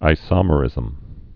(ī-sŏmə-rĭzəm)